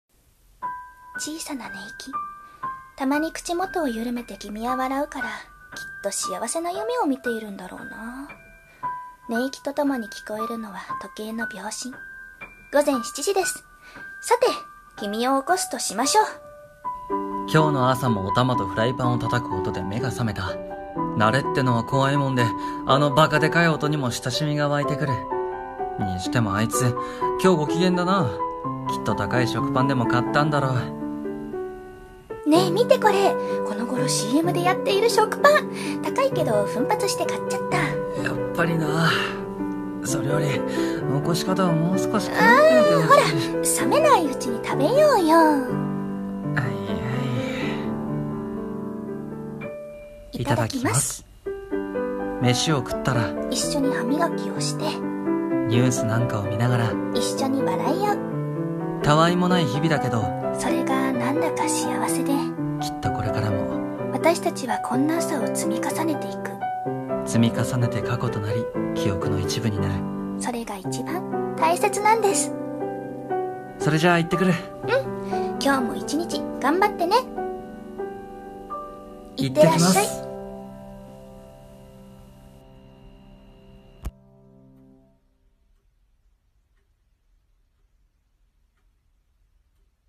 【声劇】